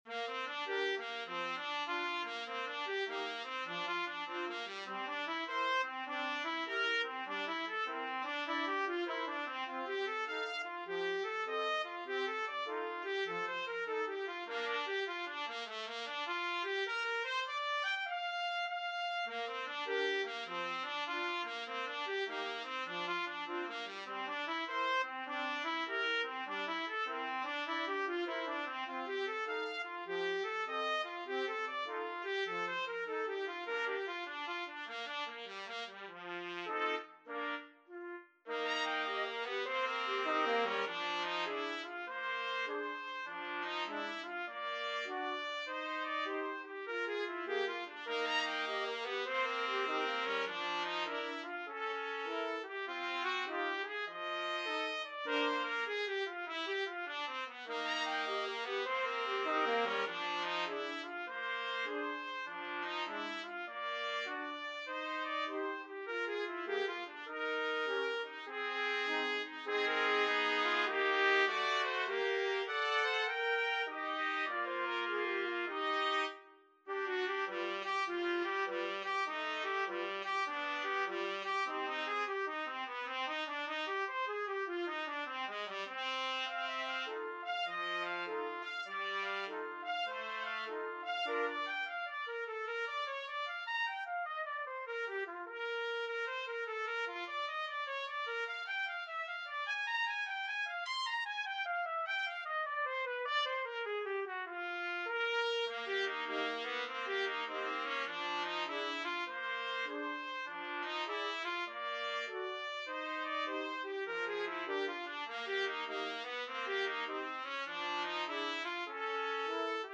4/4 (View more 4/4 Music)
Lightly = c. 100
Trumpet Quartet  (View more Advanced Trumpet Quartet Music)
Jazz (View more Jazz Trumpet Quartet Music)